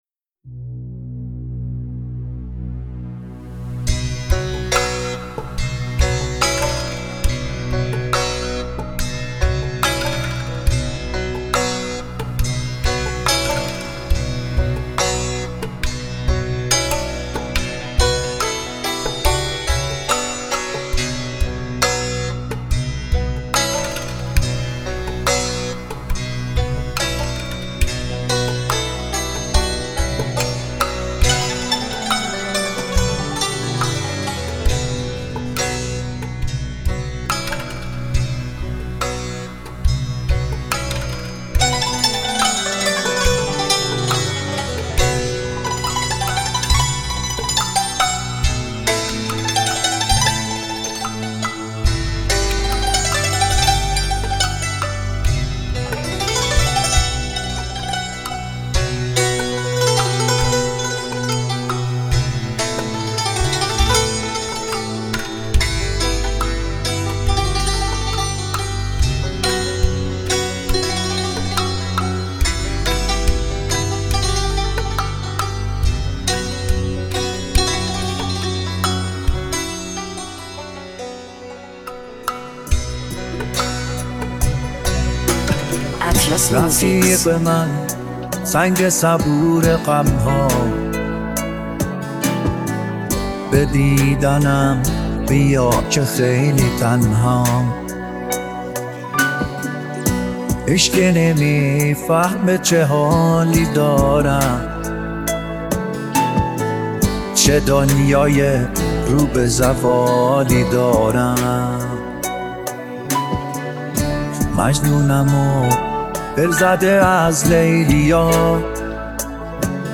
موسیقی پاپ